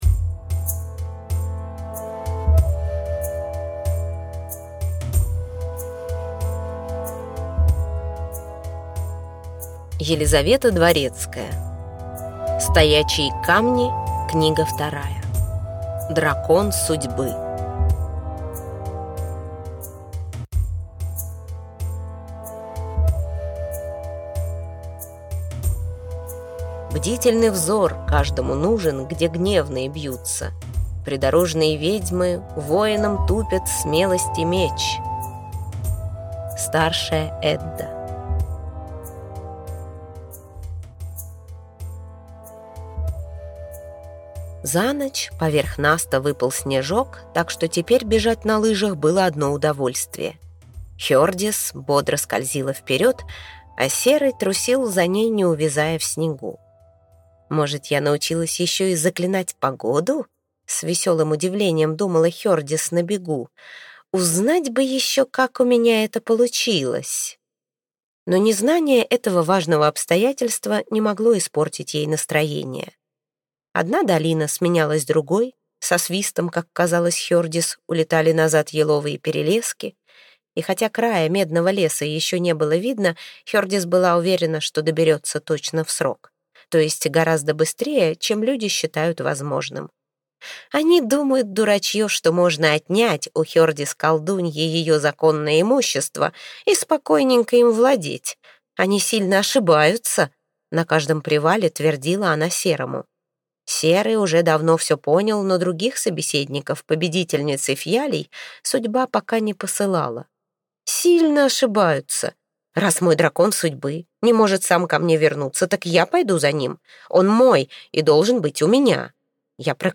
Аудиокнига Стоячие камни. Книга 2: Дракон судьбы | Библиотека аудиокниг